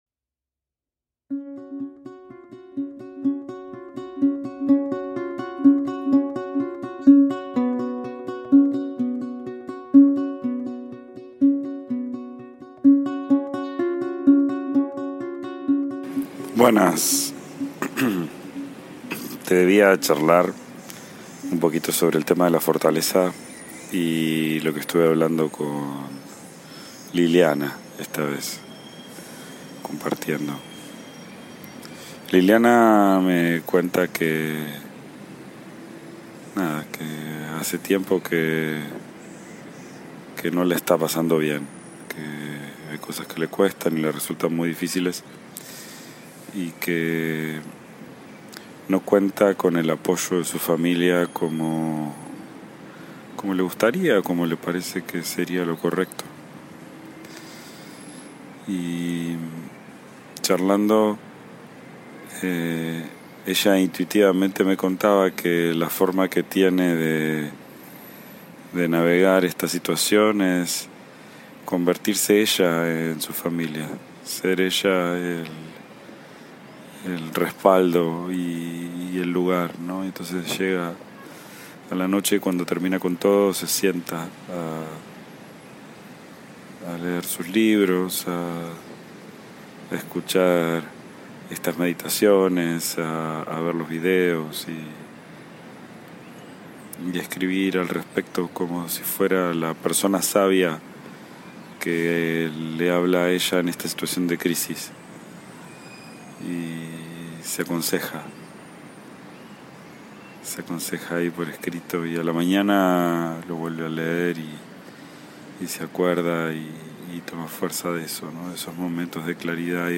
IMPORTANTE: Esta serie fue grabada durante una época de muchos viajes, directamente en mi teléfono móvil. La calidad del audio no está a la altura de lo que escucharás en las siguientes series y episodios.